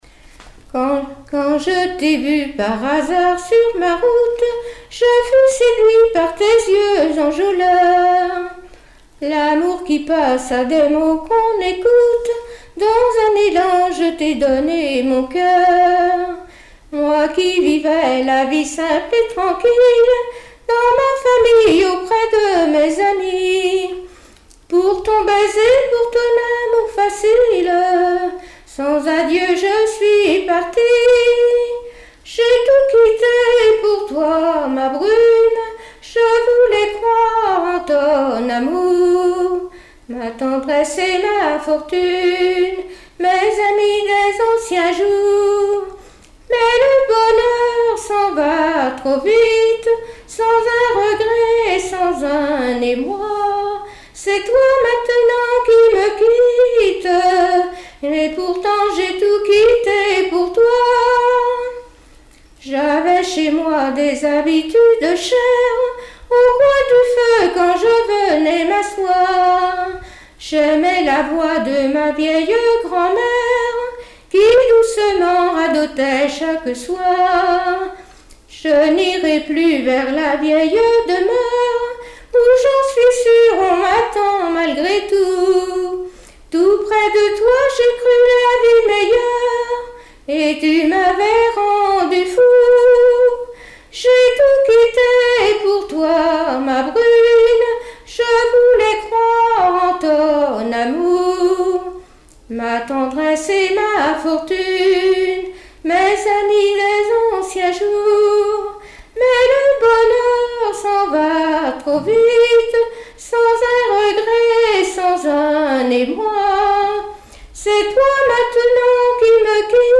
Mémoires et Patrimoines vivants - RaddO est une base de données d'archives iconographiques et sonores.
Genre strophique
Témoignages et chansons
Pièce musicale inédite